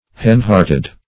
Hen-hearted \Hen"-heart`ed\